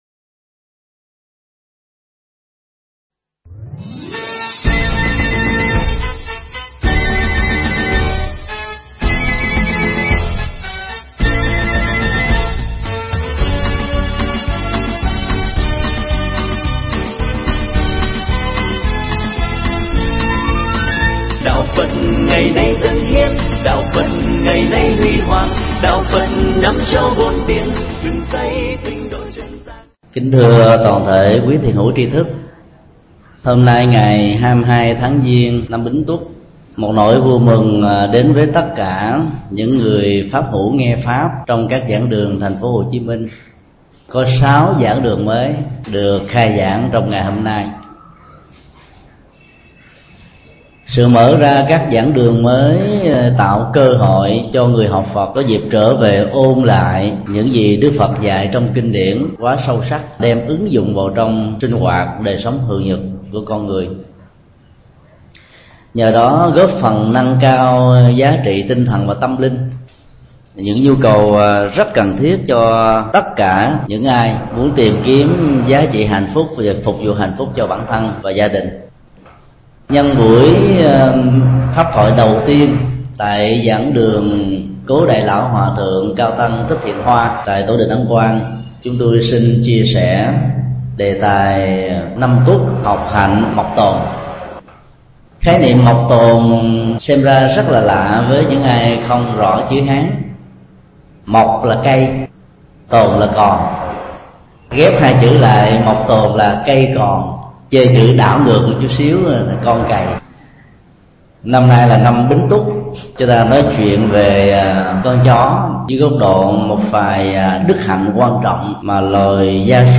Mp3 Pháp Thoại Nói Về Hạnh Mộc Tồn – Thượng Tọa Thích Nhật Từ Giảng tại Chùa Ấn Quang, ngày 19 tháng 2 năm 2006